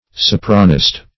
\So*pra"nist\